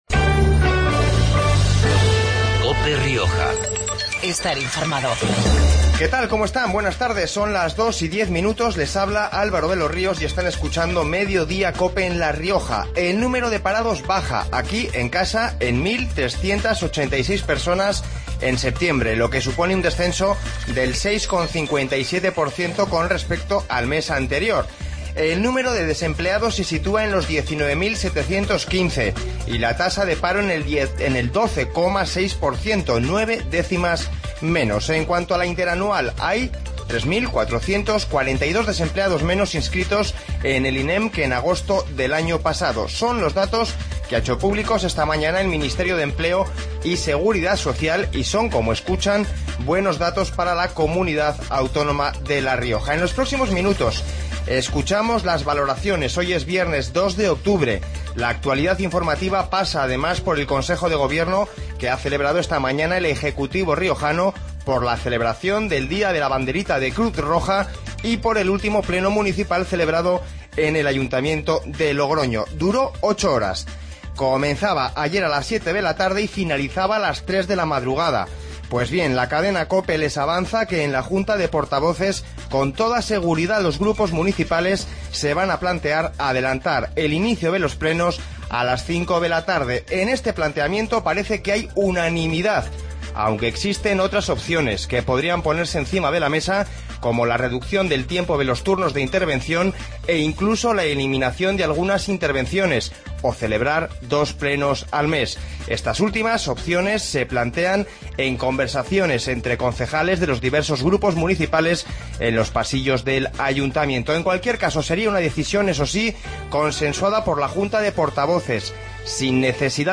Informativo Mediodia 02-10